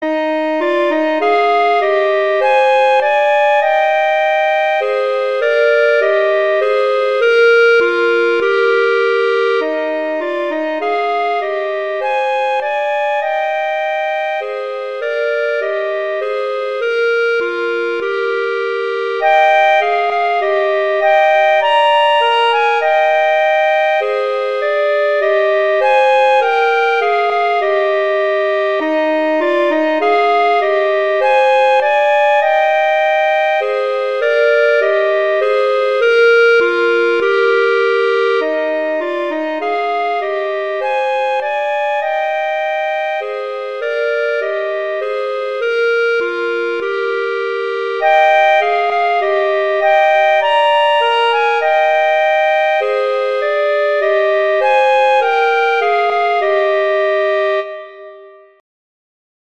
Instrumentation: two clarinets
arrangements for two clarinets